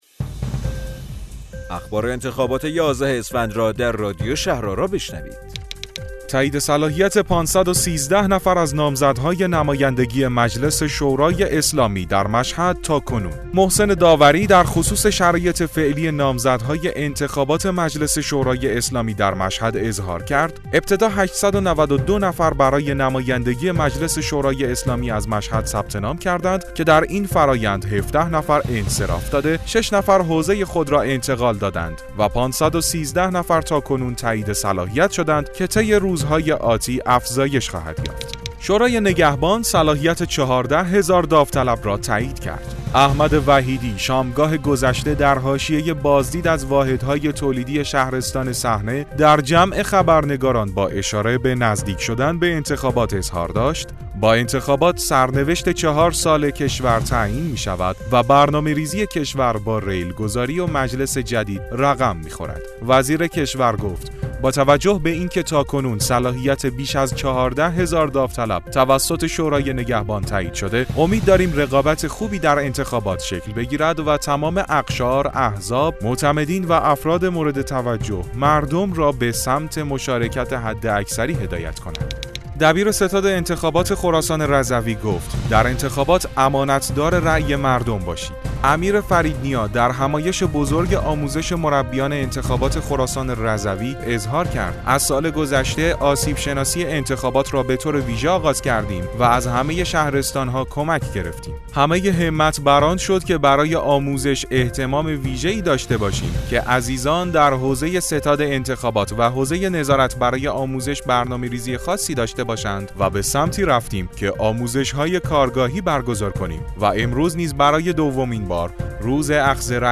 رادیو شهرآرا، پادکست خبری انتخابات یازدهم اسفندماه است.